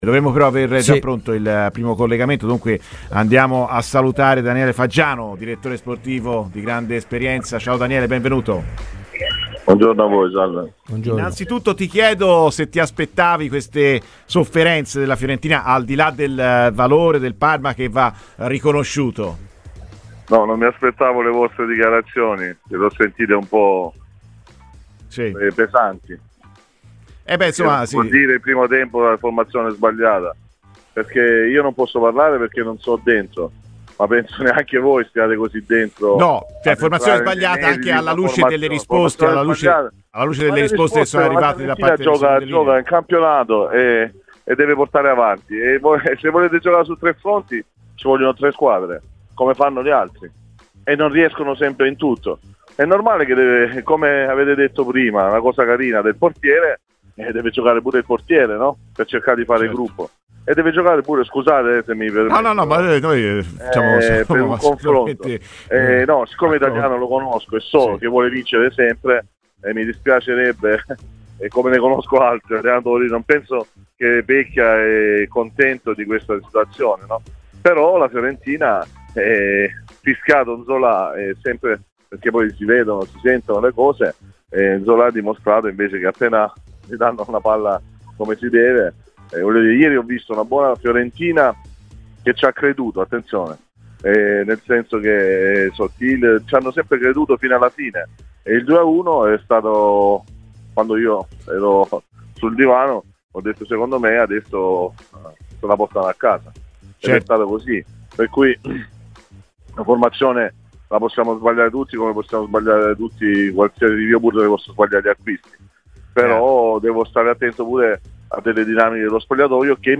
PER L'INTERVISTA COMPLETA ASCOLTA IN NOSTRO PODCAST